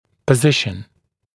[pə’zɪʃn][пэ’зишн]положение, местоположение